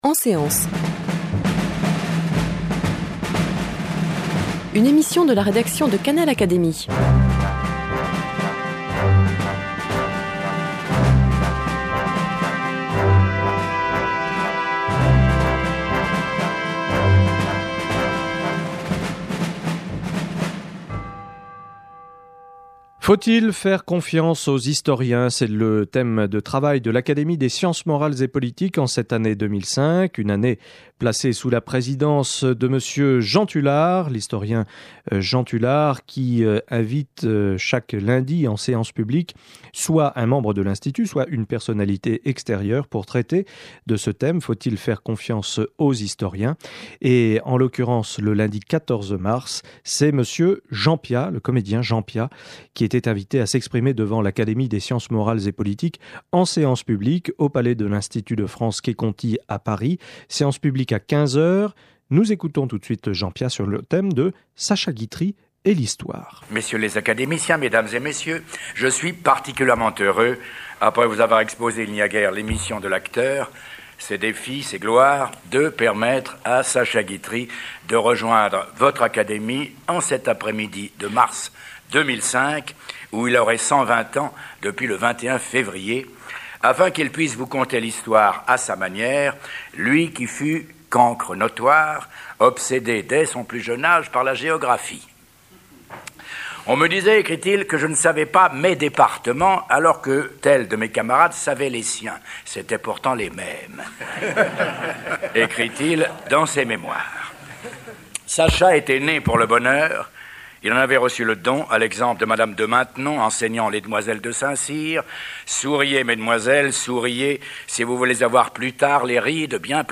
Communication de Jean Piat prononcée en séance publique devant l’Académie des sciences morales et politiques le lundi 14 mars 2005.